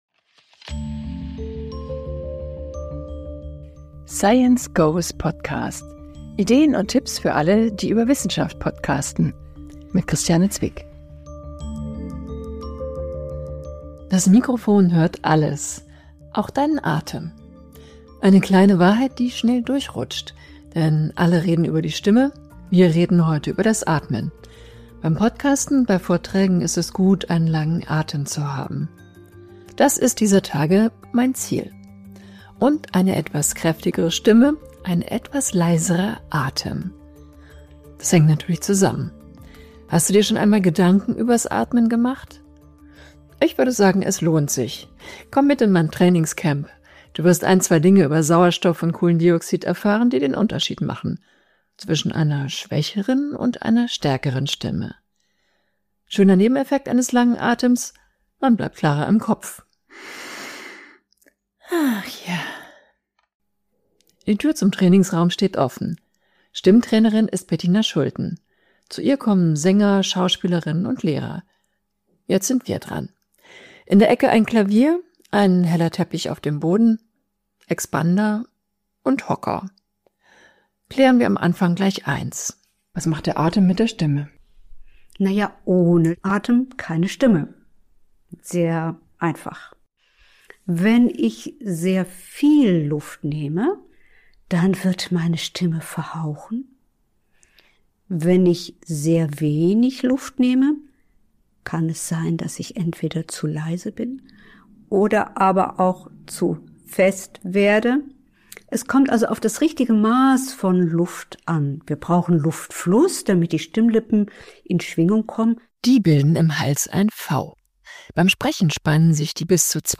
Beschreibung vor 4 Monaten Das Mikrofon hört alles, auch unseren Atem… wenn er etwas zu laut ist.